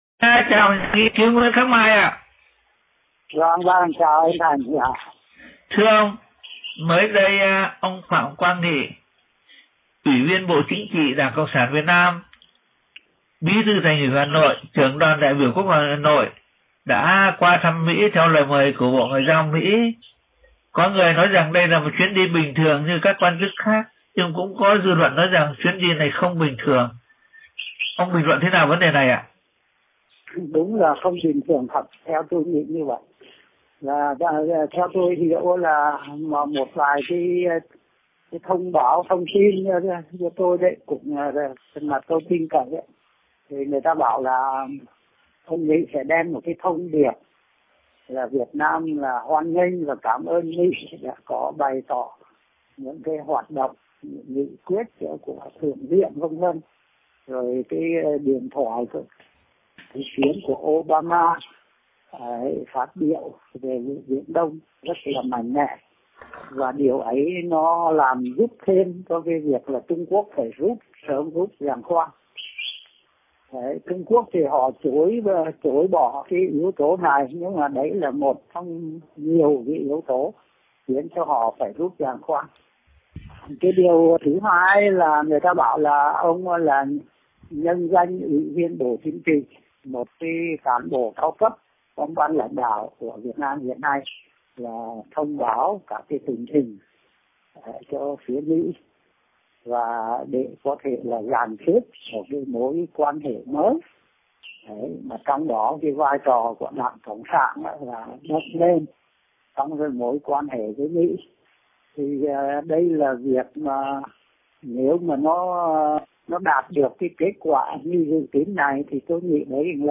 Người phỏng vấn gửi BVN .